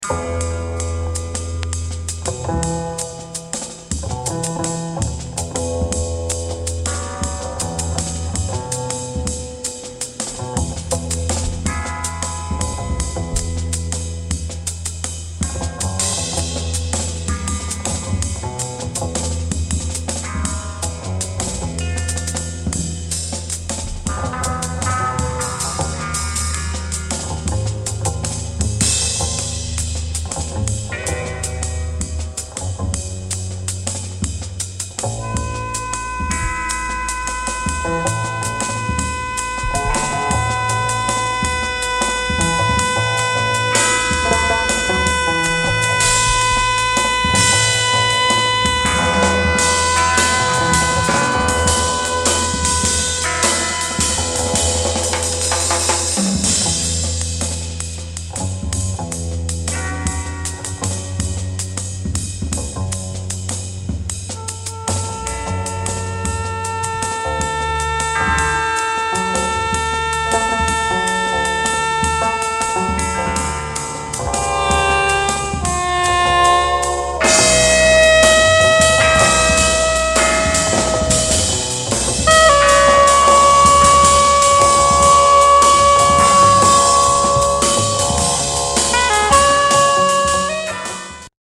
A blend of rock and free improv.